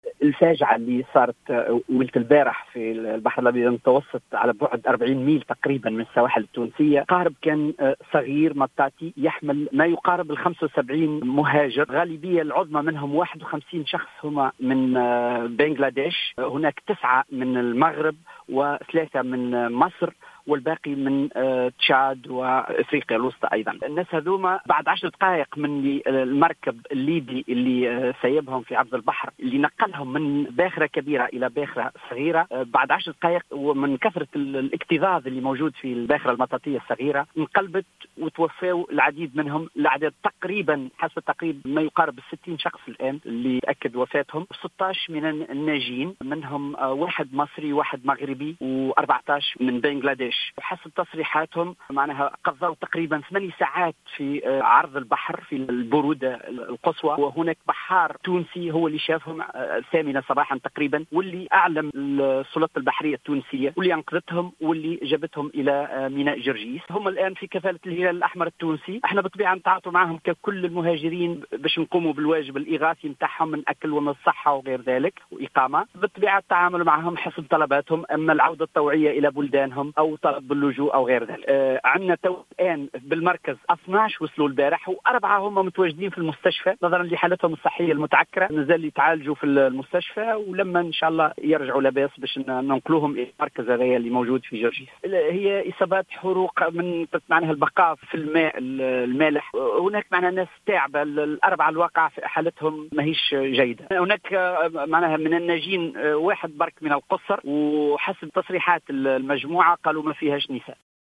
في تصريح للجوهرة اف ام صباح اليوم السبت 11 ماي 2019